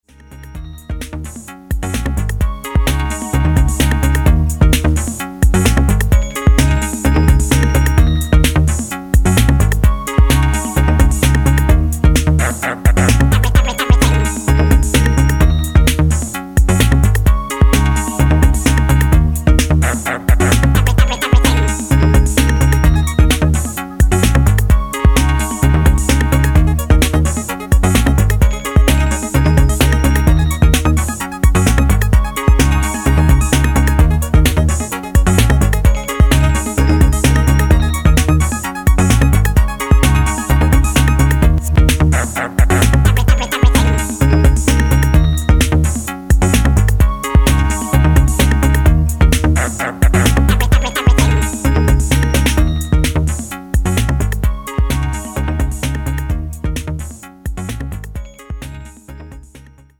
Electro Electronix Techno Detroit